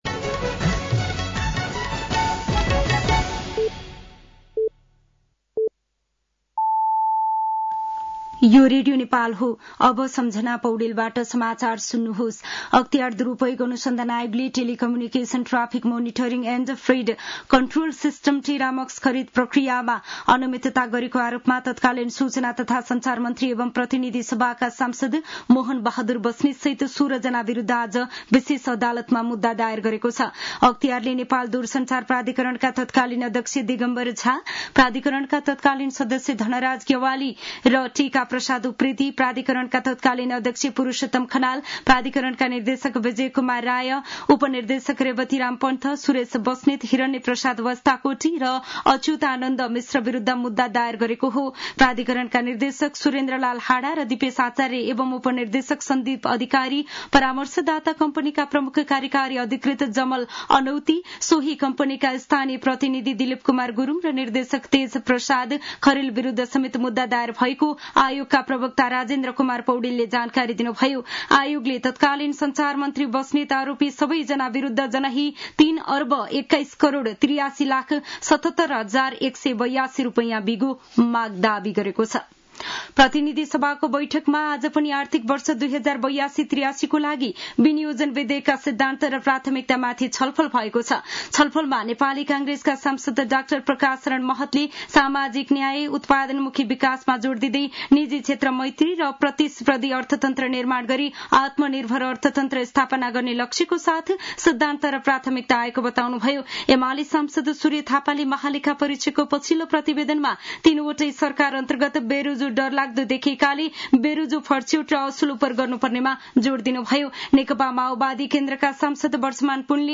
साँझ ५ बजेको नेपाली समाचार : १ जेठ , २०८२
5-pm-news-2.mp3